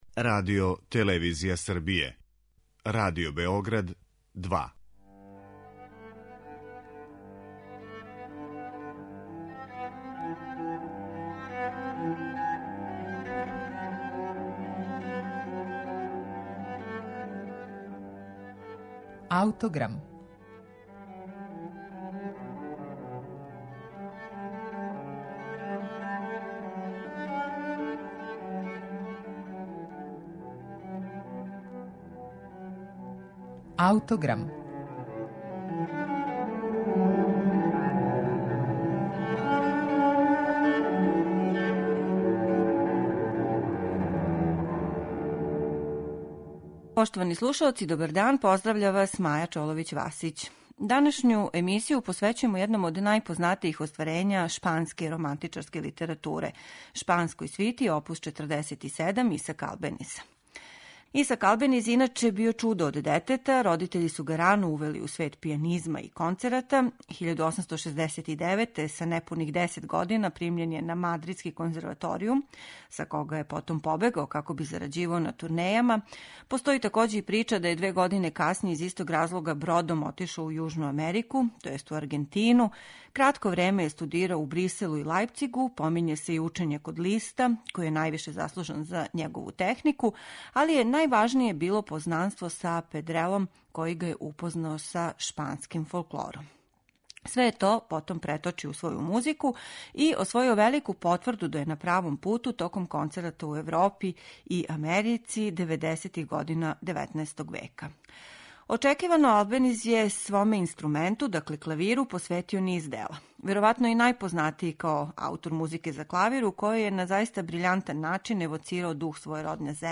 У опусу овог шпанског Листа (како су га називали), међу најпознатијим клавирским композицијама је "Шпанска свита оп. 47", писана за клавир током 1886. и 87. у част шпанске краљице.